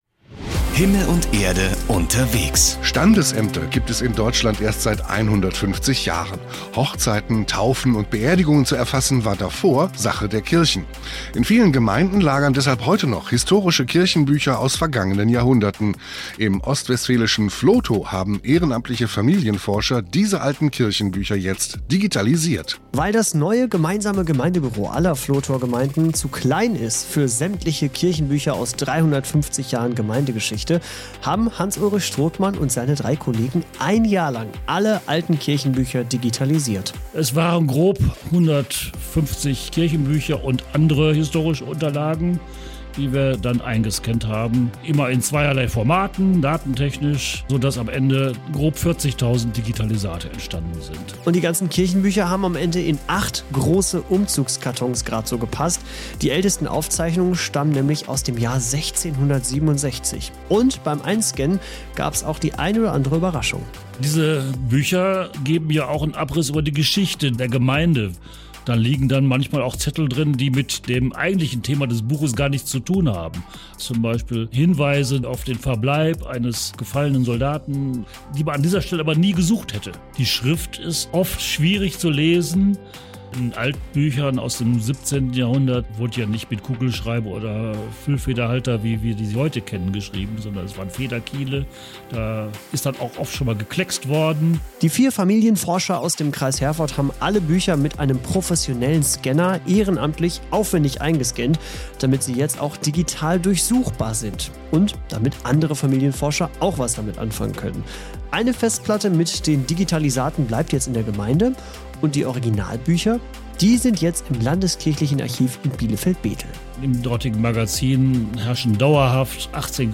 Hinweis auf einen Radio-Beitrag unserer Arbeitsgruppe bei Himmel und Erde am 05.01.2025